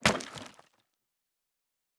Fantasy Interface Sounds
Wood 10.wav